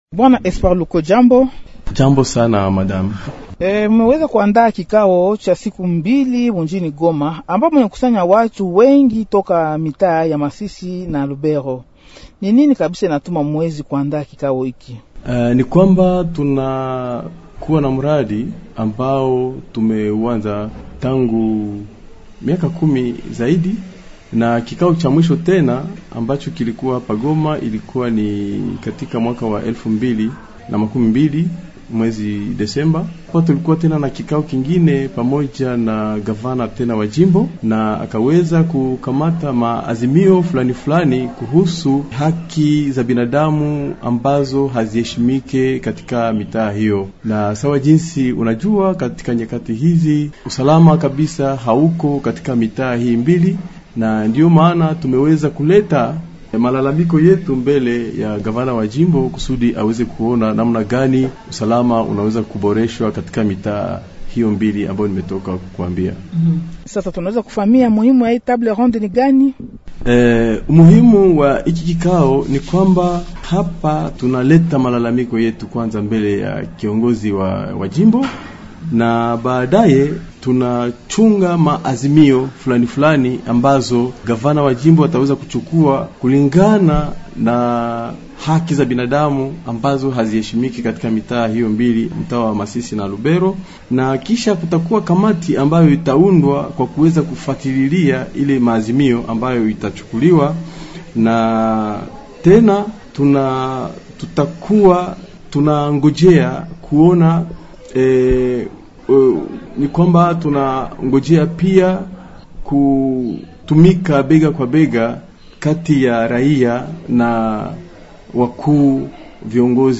Shirika hili lilipanga wiki iliyopita huko Goma kiako cha mazungumzo kuhusu Kupunguza hatari za Ulinzi zilizorekodiwa katika tarafa za Masisi na Lubero. Anahojiwa